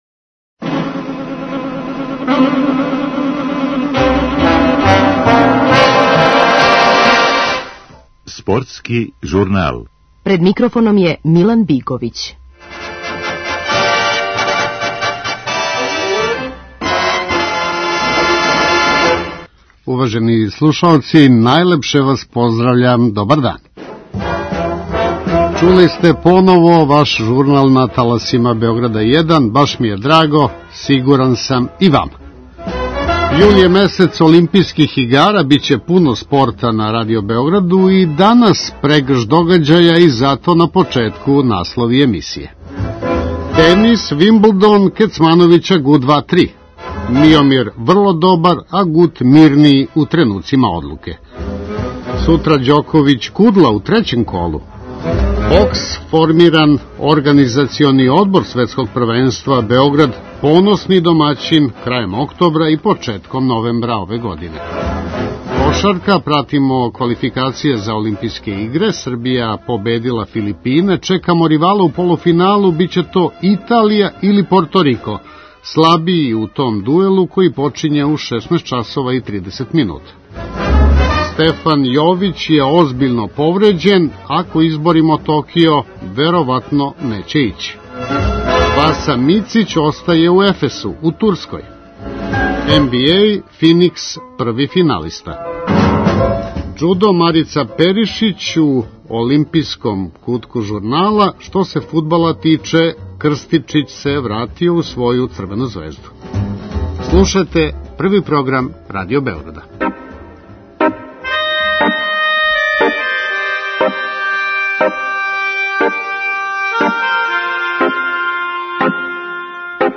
После краће паузе на таласе Првог програма, у летњој шеми, враћа се Спортски журнал, дневни преглед најважнијих спортских догађаја дана.